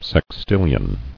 [sex·til·lion]